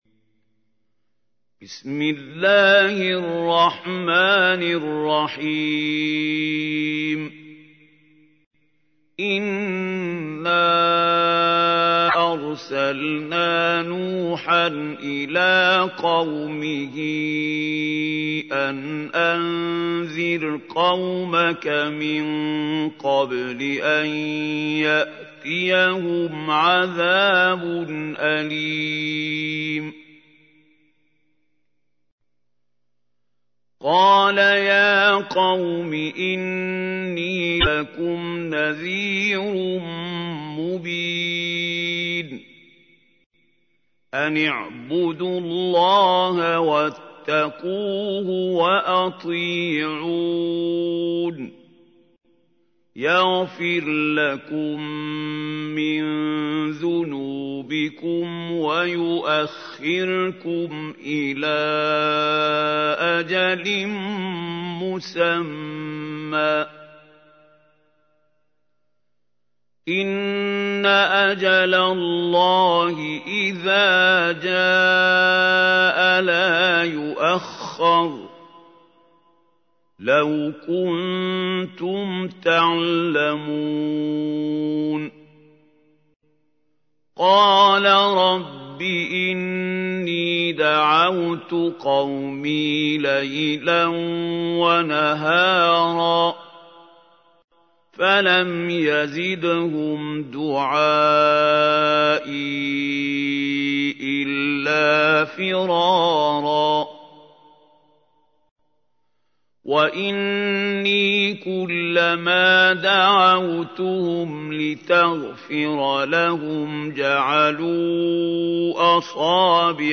تحميل : 71. سورة نوح / القارئ محمود خليل الحصري / القرآن الكريم / موقع يا حسين